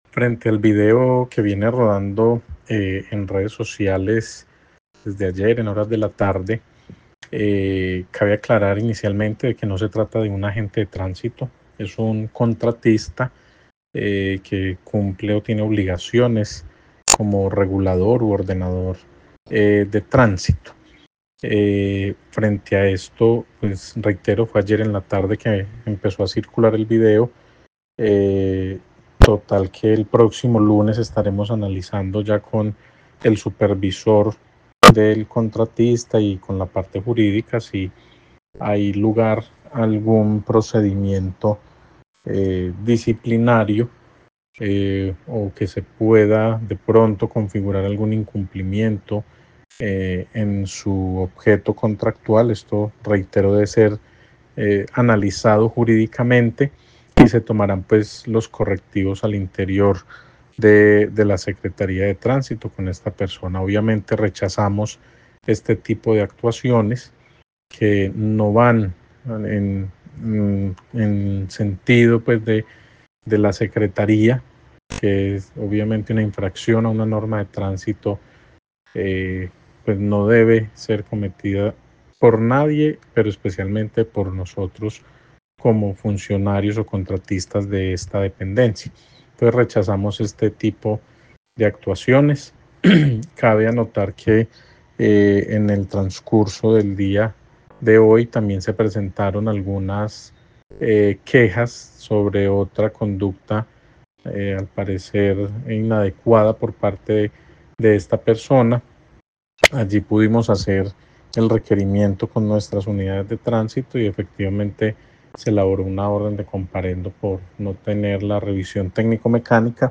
Secretario de Tránsito de Armenia, Daniel Jaime Cast